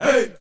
SouthSide Chant (15).wav